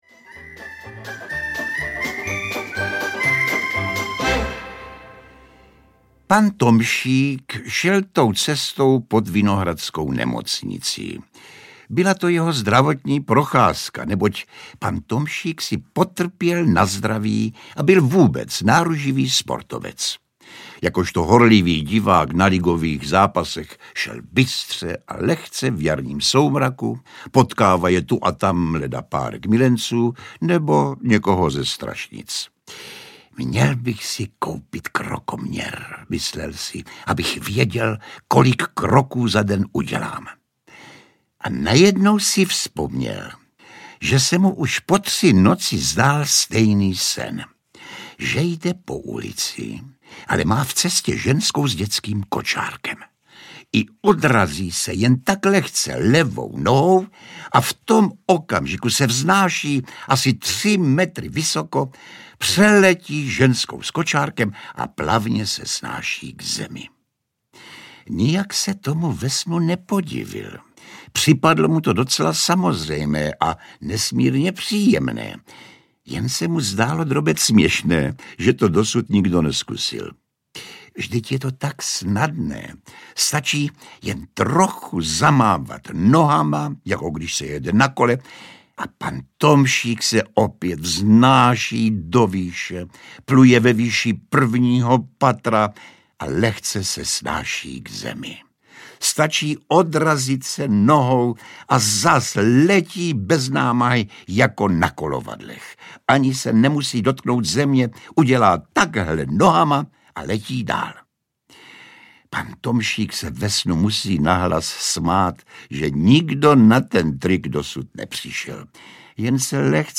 Ukázka z knihy
• InterpretJosef Somr, Jan Kanyza, Tereza Bebarová